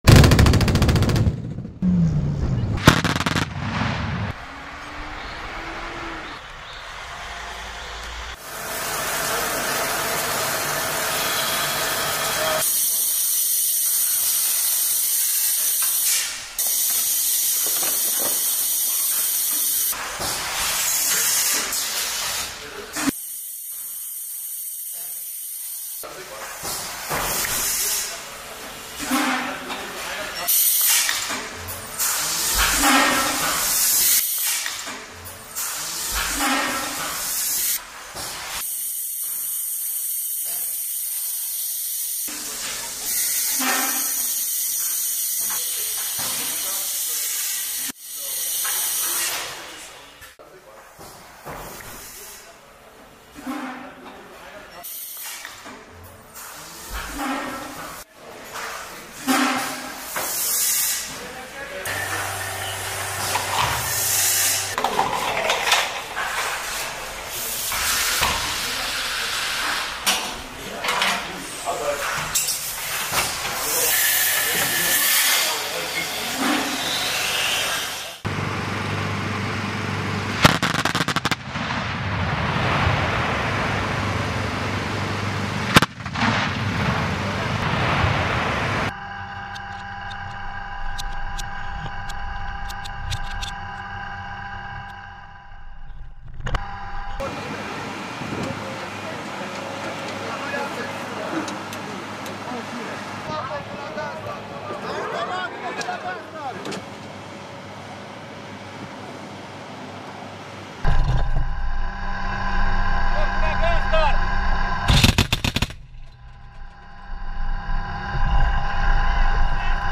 Oerlikon 35mm – Inside Factory and Rapid-Fire Anti-Air Gun in Action